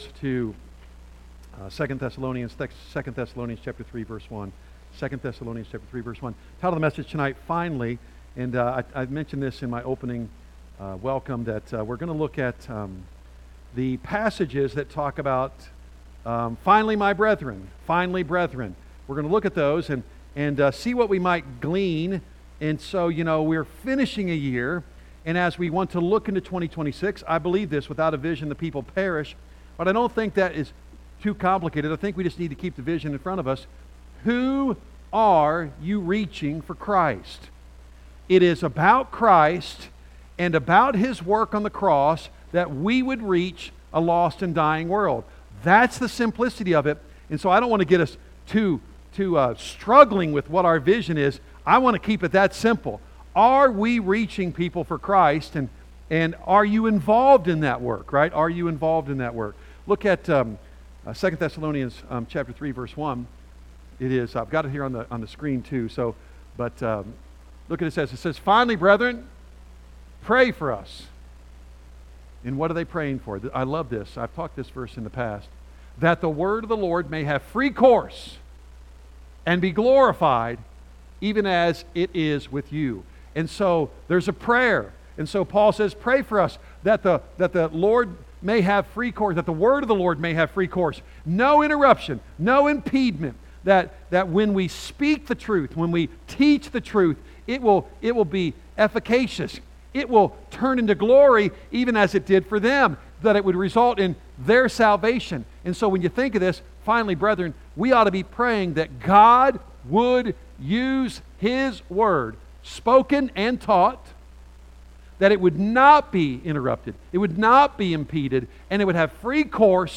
A message from the series "Standalone Sermons."